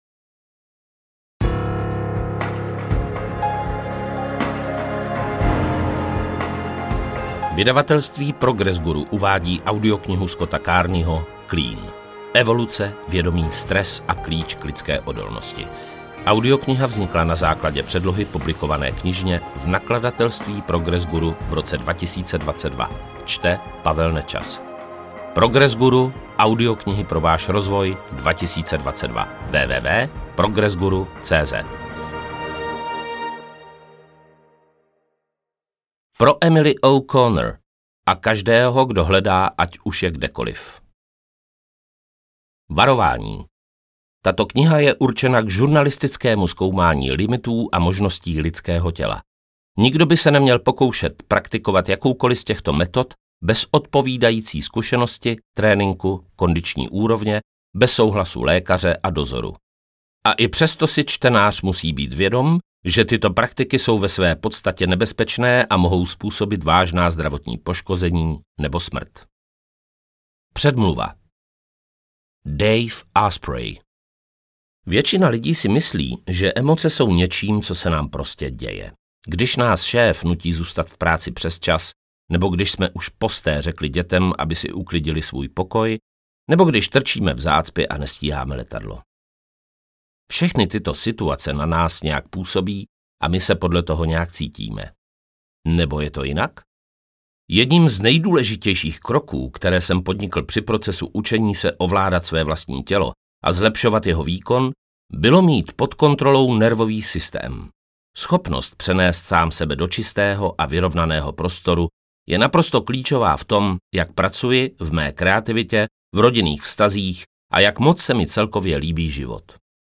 Interpret:  Pavel Nečas
AudioKniha ke stažení, 16 x mp3, délka 8 hod. 58 min., velikost 492,3 MB, česky